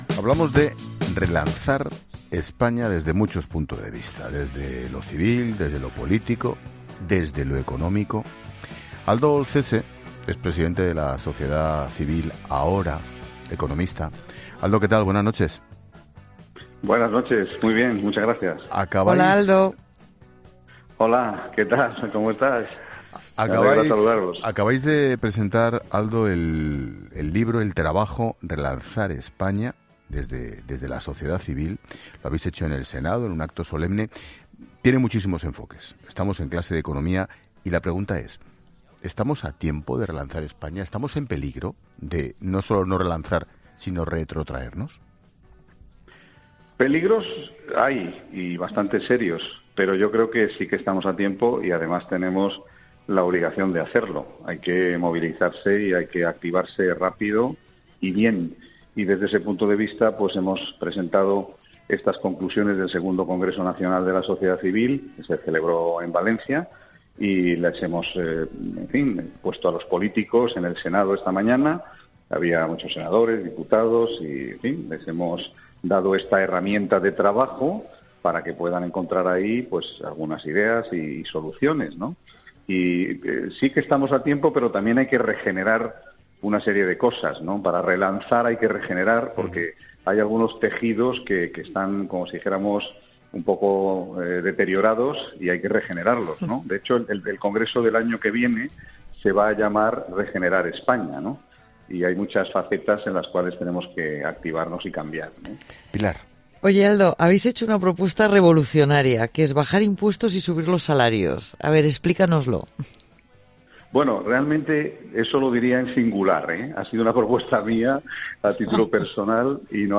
Un economista sorprende a Ángel Expósito con la fecha del final de la guerra en Ucrania: “Me da la impresión”